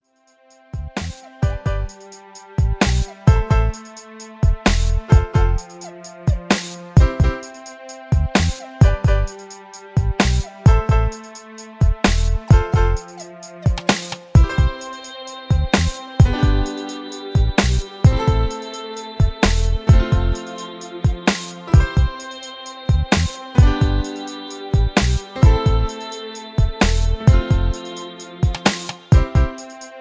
A fusion of Reggae Calypso & Soul. Fast tempo